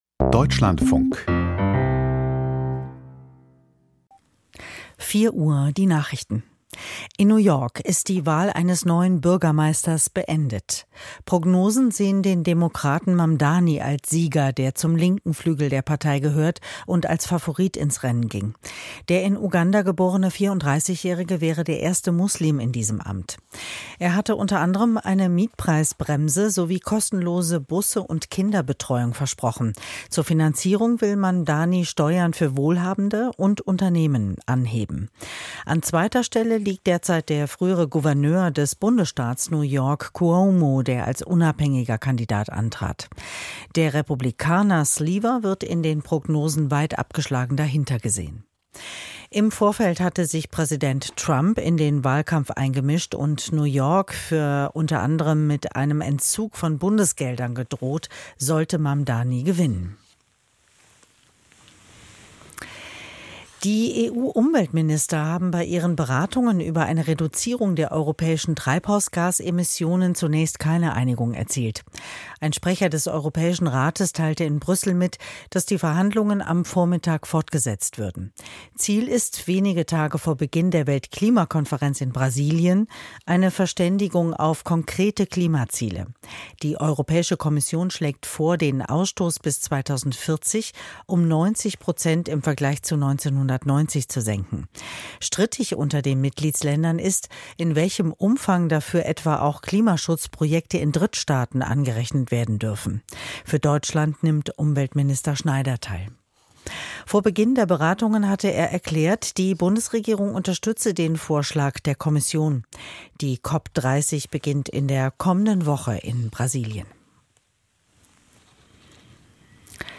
Die Nachrichten vom 05.11.2025, 04:00 Uhr
Aus der Deutschlandfunk-Nachrichtenredaktion.